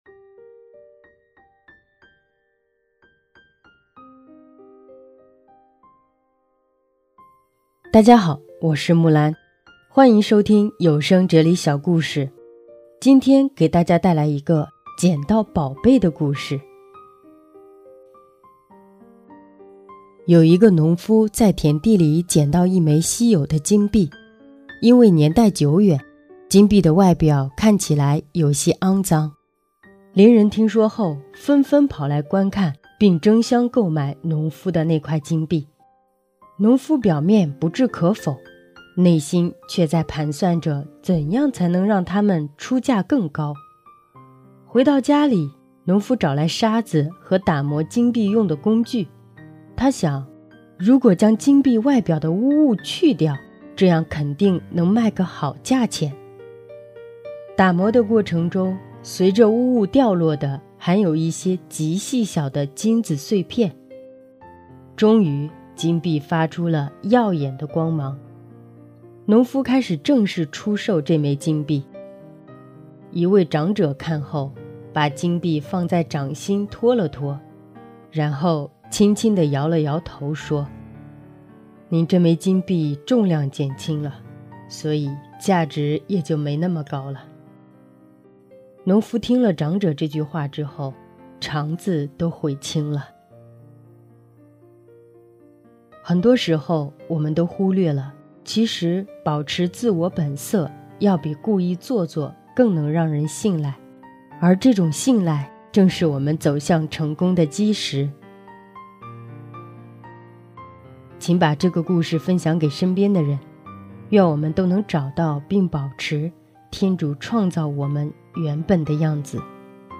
今天故事结尾的歌曲是第三届“真理杯”全球华语圣歌创作大赛的优秀奖作品《我的一切你全都知道》。